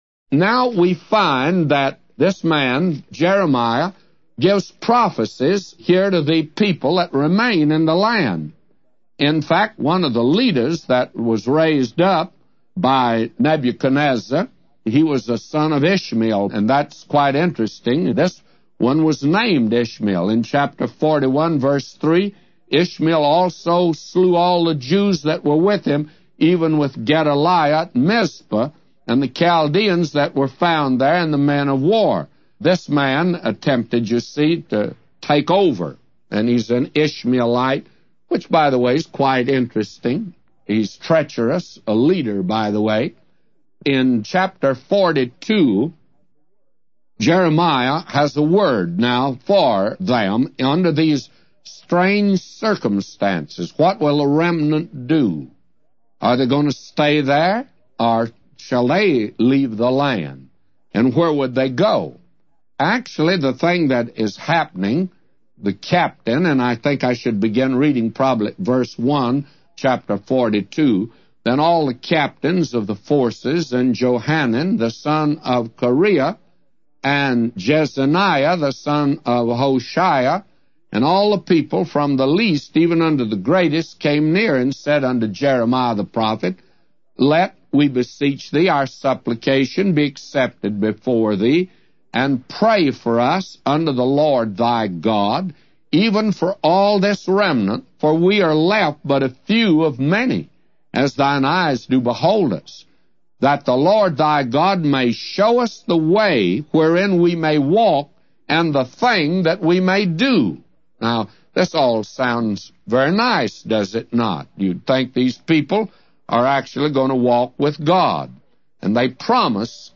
A Commentary By J Vernon MCgee For Jeremiah 41:1-999